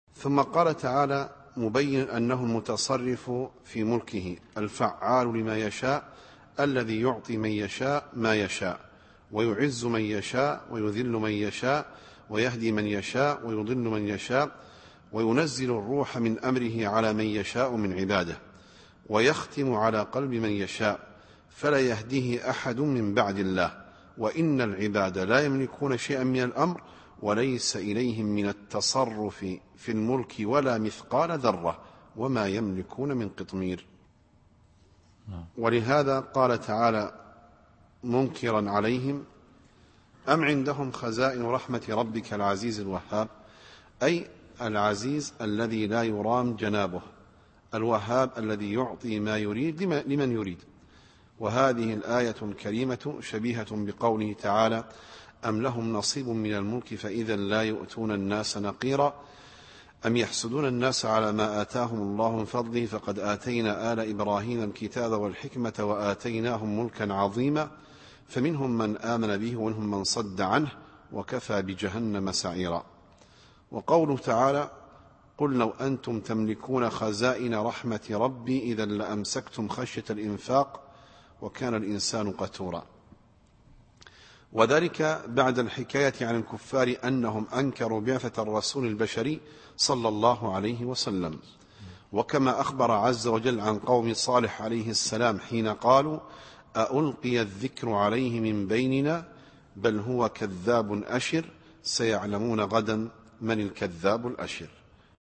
التفسير الصوتي [ص / 9]